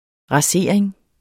Udtale [ ʁɑˈseˀɐ̯eŋ ]